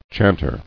[chant·er]